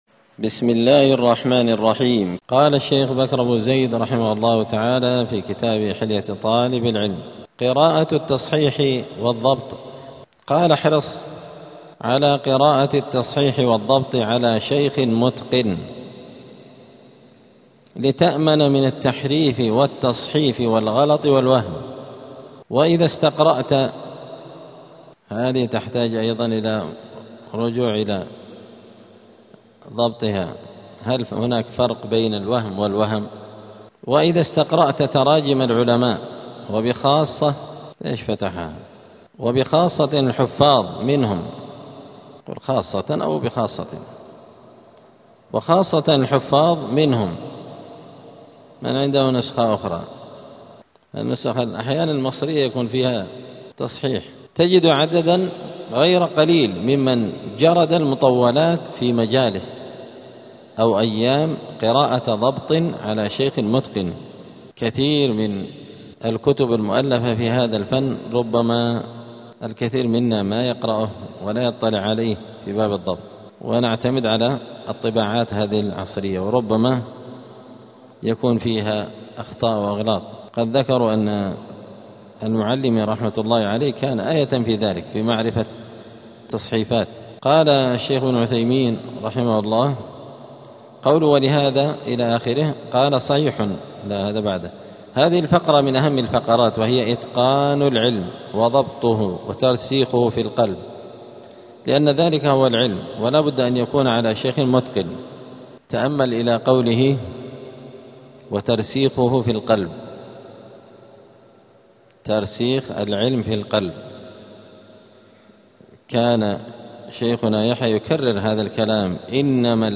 *الدرس الثمانون (80) فصل آداب الطالب في حياته العلمية {قراءة التصحيح والضبط}.*